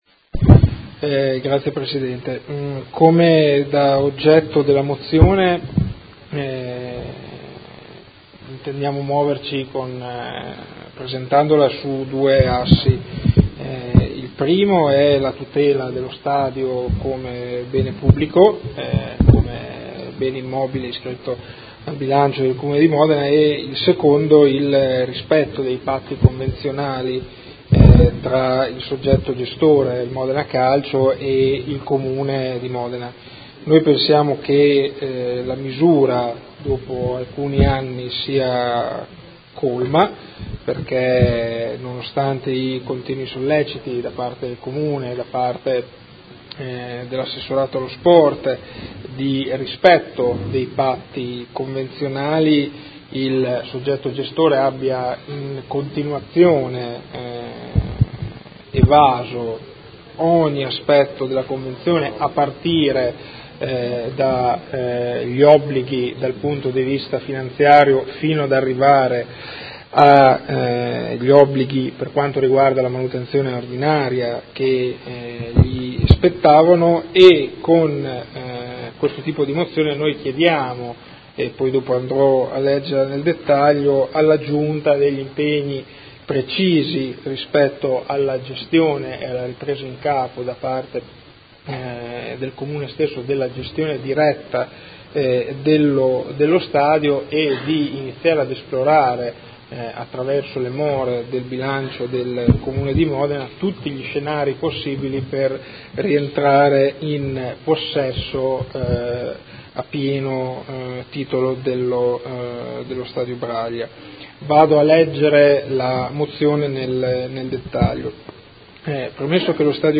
Andrea Bortolamasi — Sito Audio Consiglio Comunale